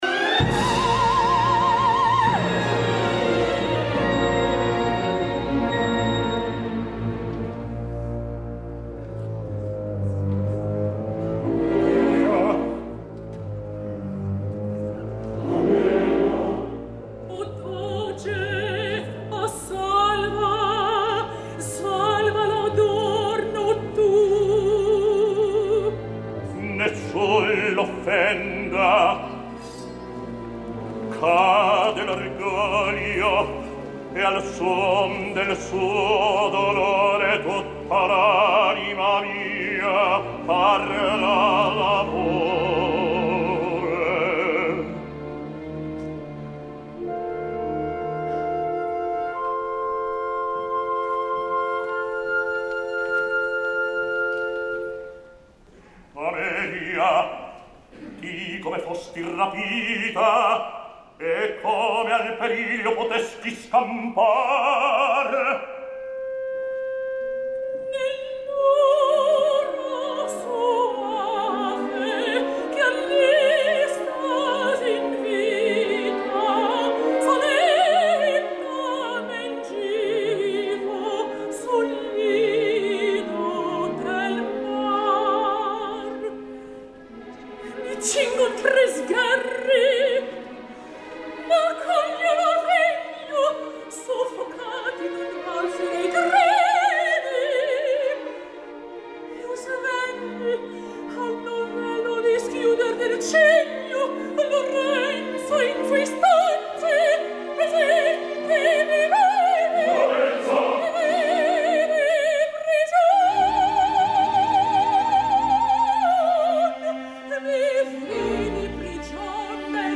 Avui us porto al blog, el Simon Boccanegra de Plácido Domingo al MET de New York del dia 2 de febrer de 2010.
Plácido Domingo no és un baríton i menys dramàtic, per tant aquest Simon haurà de ser escoltat sense fer les naturals comparacions amb els barítons que com Tibbett, Warren o Cappuccilli, per exemple, que han estat inoblidables i referencials al interpretant-lo.
El Simon Boccanegra de Domingo , és un Simon cantat per un tenor amb un centre encara consistent, sense els greus necessaris i amb els aguts fàcils per a un tenor i que sempre sonen de tenor.